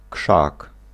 Ääntäminen
US : IPA : [ʃɻʌb]